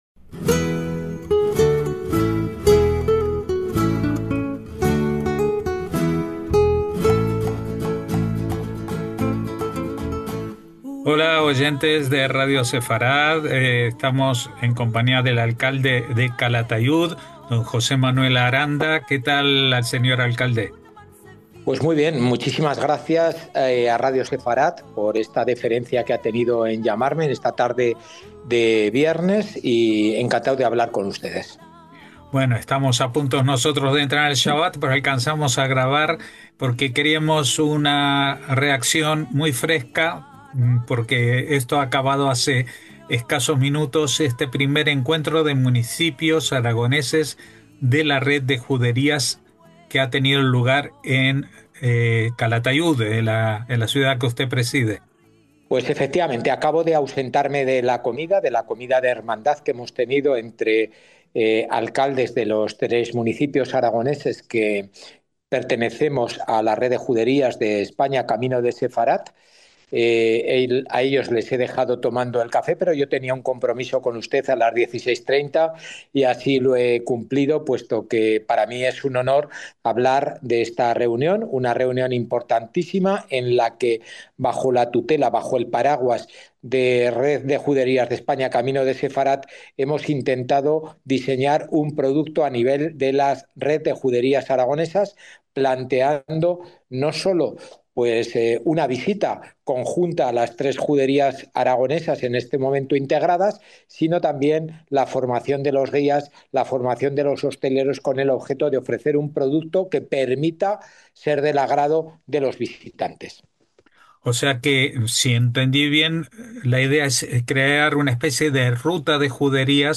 Primer encuentro de municipios aragoneses de la Red de Juderías en Calatayud, con su alcalde José Manuel Aranda